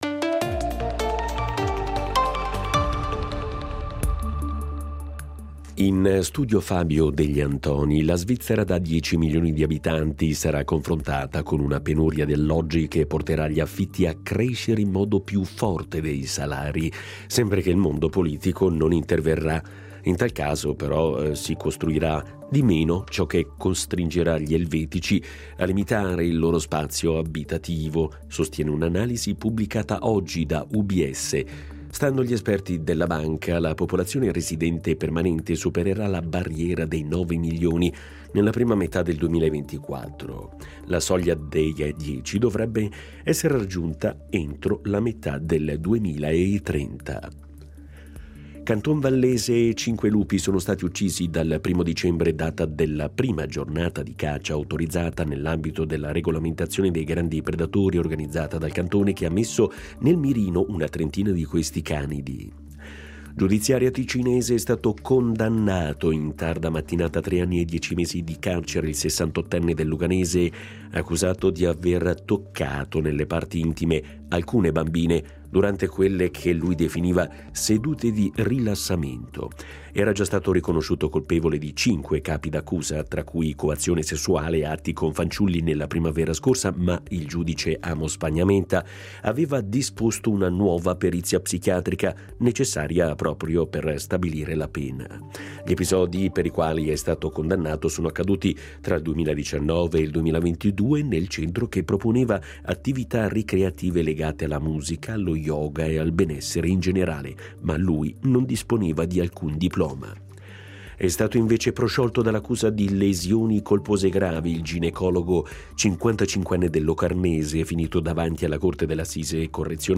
Notiziario delle 17:00 del 06.12.2023